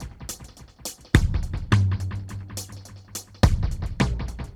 Index of /musicradar/dub-drums-samples/105bpm
Db_DrumsB_EchoKit_105-02.wav